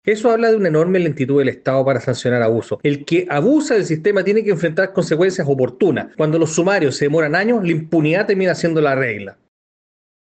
Desde la Comisión de Salud de la Cámara Baja, el diputado republicano, Agustín Romero, manifestó preocupación ante la tardanza de las gestiones estatales y sostuvo que la extensa tramitación del proceso instaura la sensación de “impunidad”.